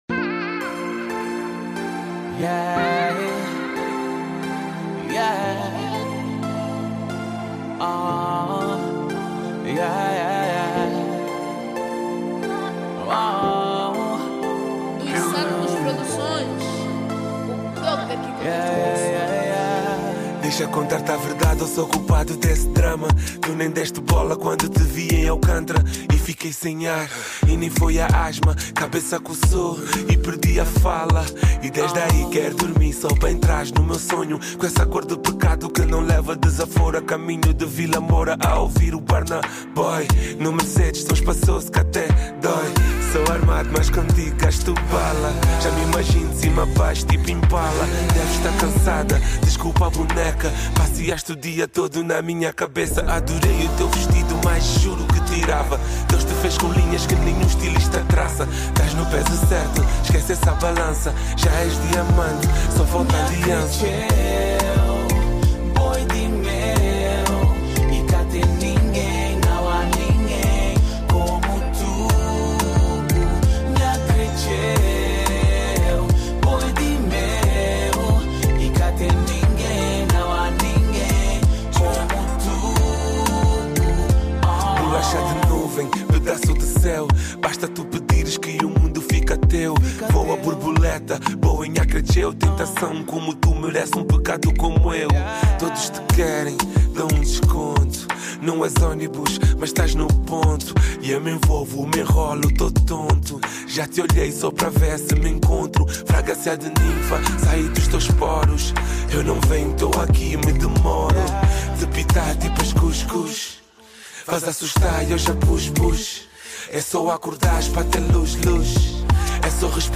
Categoria Zouk